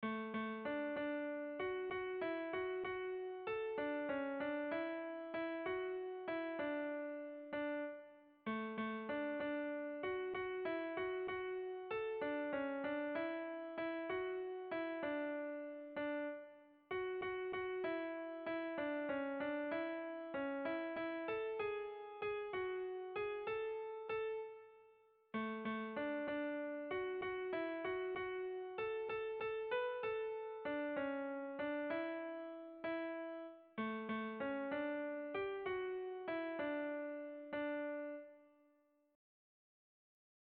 Erlijiozkoa
Zortziko handia (hg) / Lau puntuko handia (ip)
AABA2